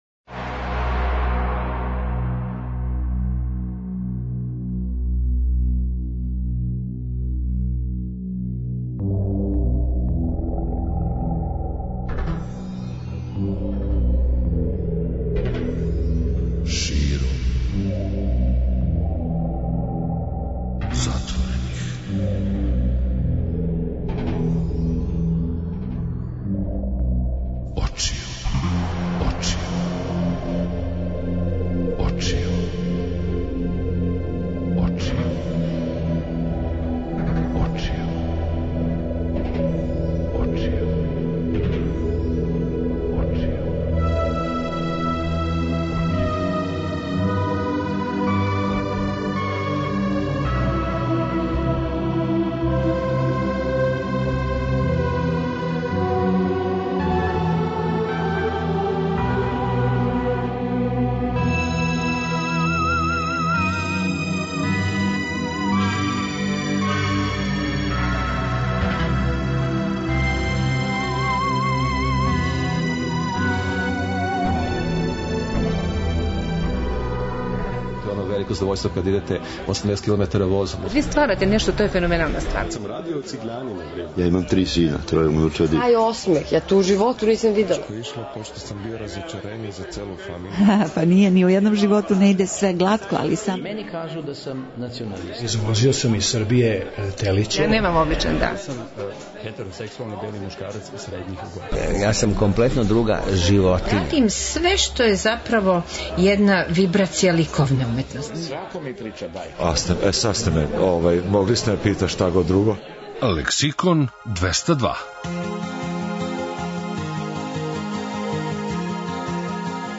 преузми : 57.29 MB Широм затворених очију Autor: Београд 202 Ноћни програм Београда 202 [ детаљније ] Све епизоде серијала Београд 202 Блузологија Свака песма носи своју причу Летње кулирање Осамдесете заувек!